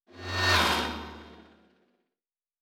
Fly By 02_7.wav